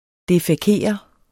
Udtale [ defεˈkeˀʌ ]